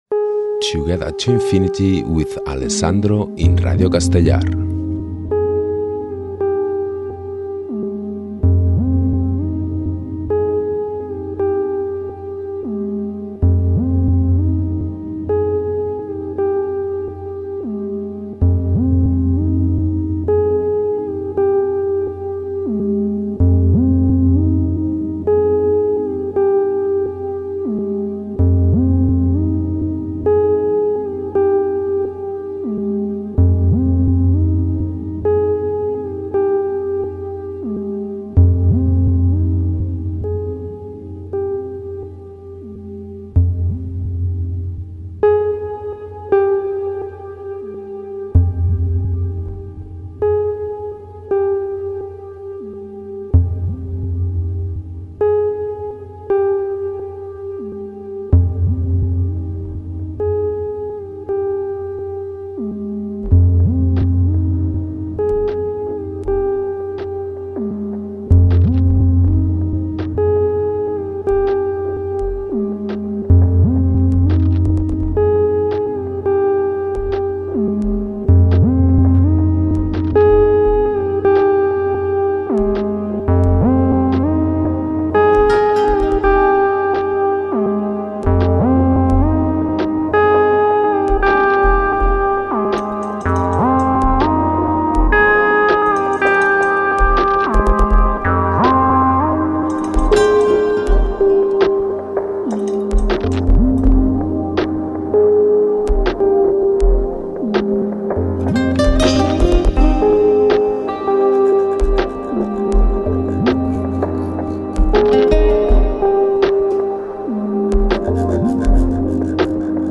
música electrònica amb connexions eivissenques